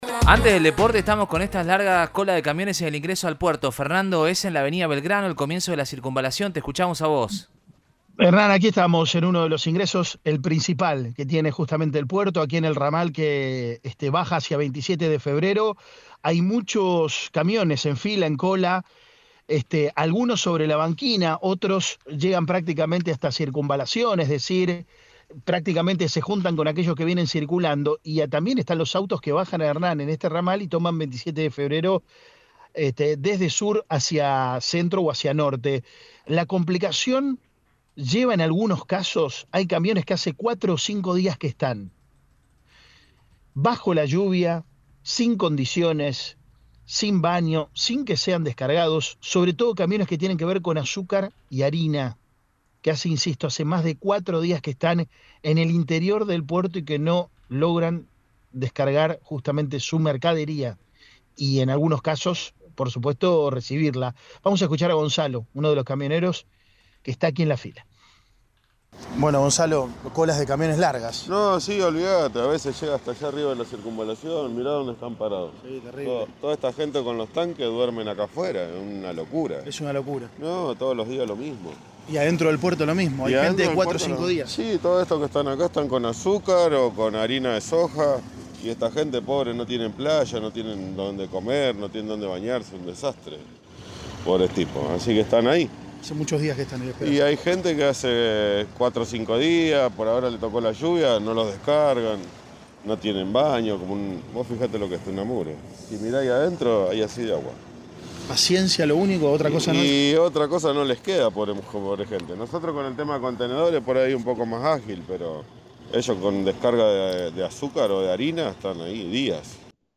Informe de